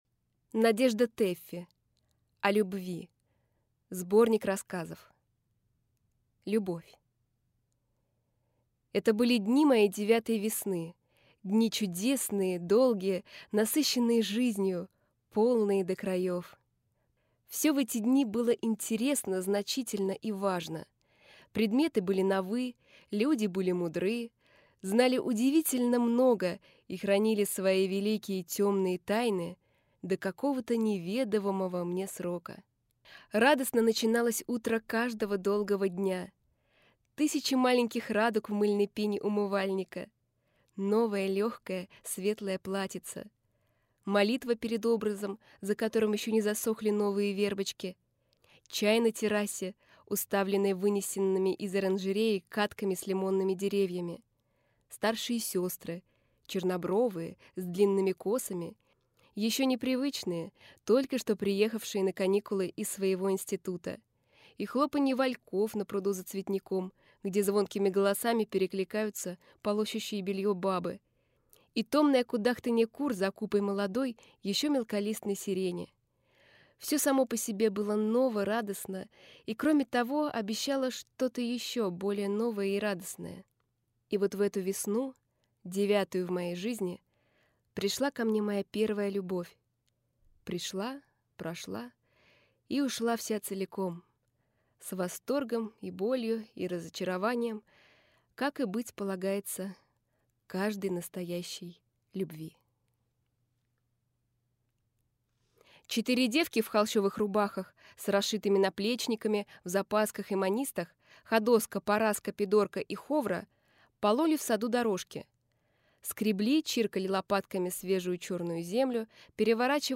Аудиокнига О любви (сборник) | Библиотека аудиокниг
Прослушать и бесплатно скачать фрагмент аудиокниги